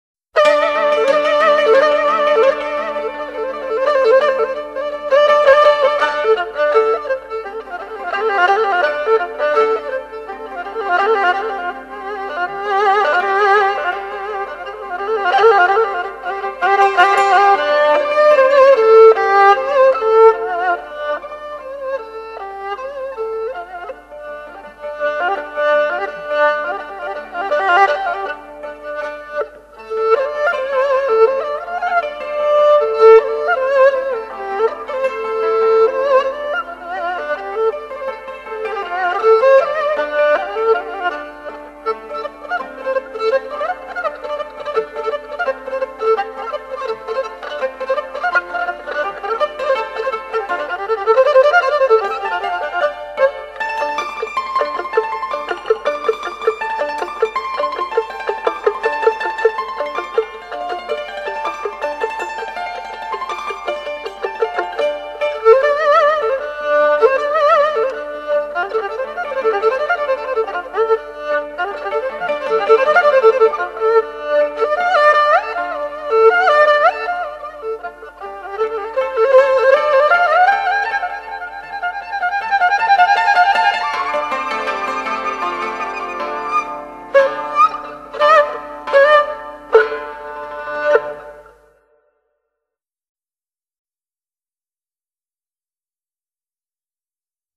情绪兴奋昂扬